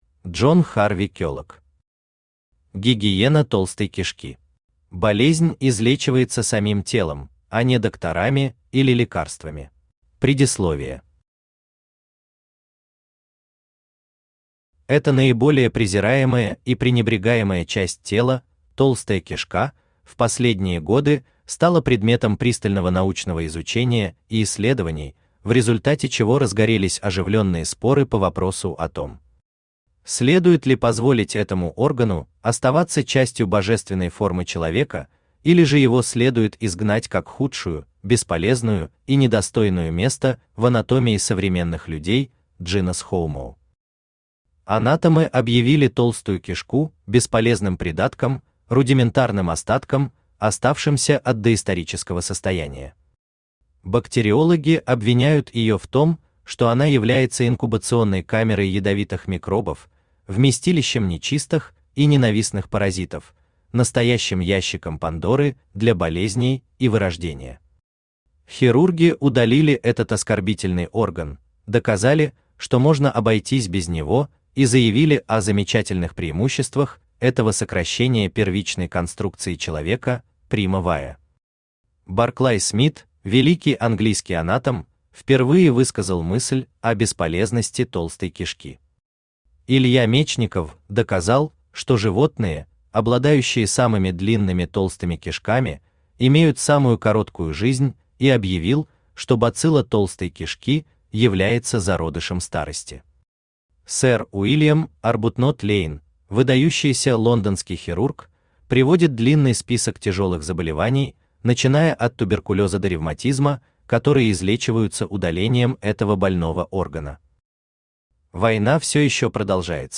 Аудиокнига Гигиена толстой кишки | Библиотека аудиокниг
Aудиокнига Гигиена толстой кишки Автор Джон Харви Келлог Читает аудиокнигу Авточтец ЛитРес.